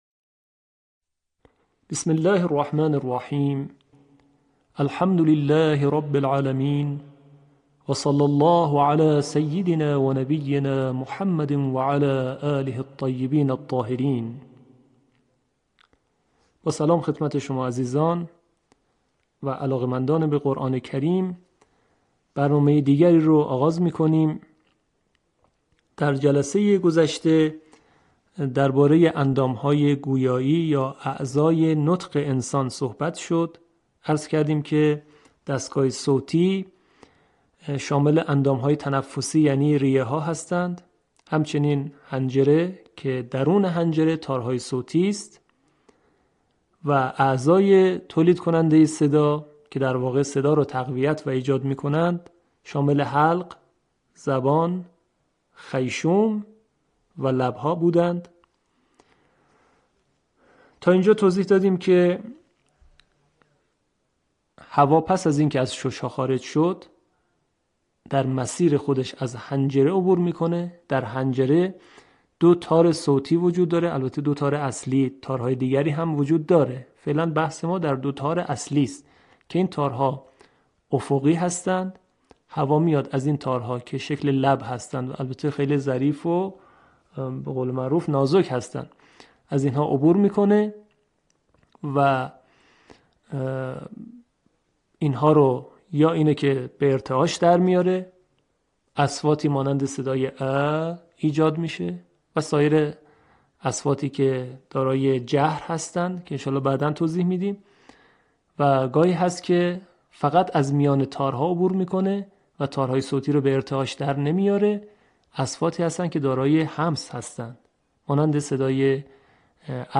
آموزش تجوید